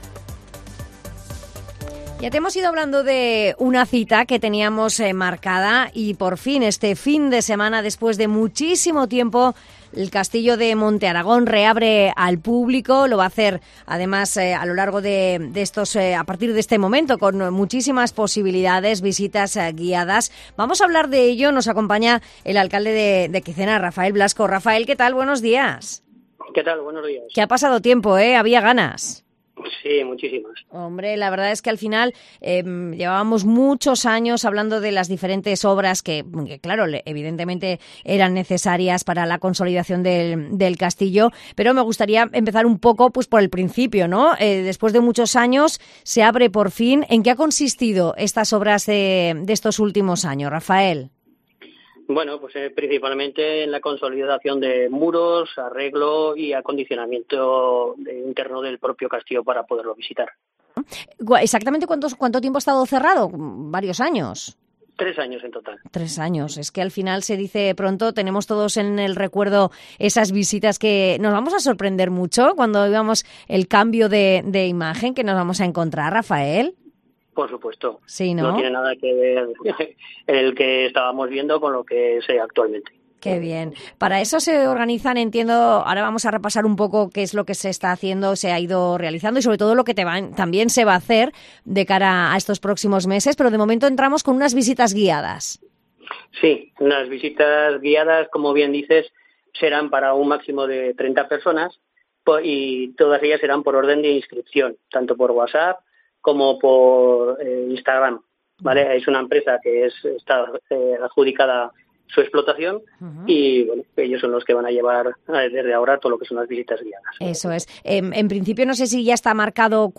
El alcalde de Quicena, Rafael Blasco habla de la próxima apertura del castillo de Monteraragón